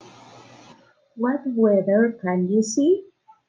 Flashcards con palabras e imágenes sobre las estaciones y palabras clave de elementos asociados del clima en ingles, puedes escuchar la pronunciación haciendo clic en el botón play.